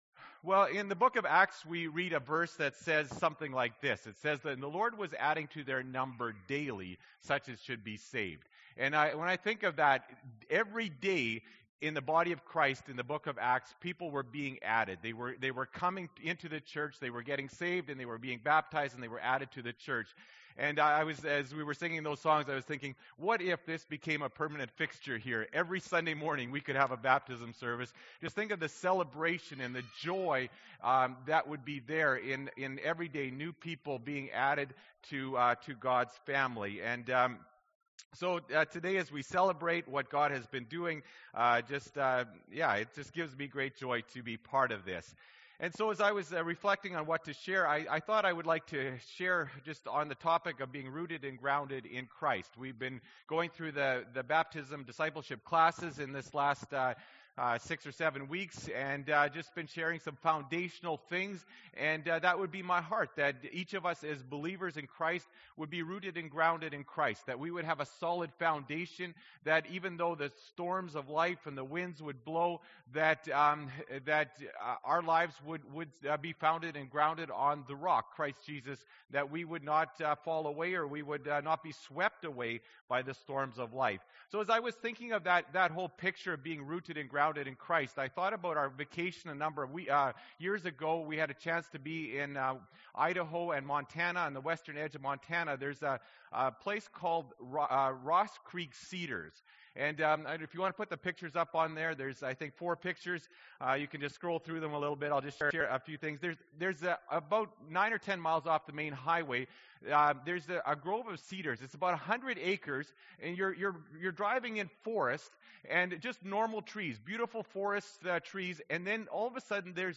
April 19, 2015 – Sermon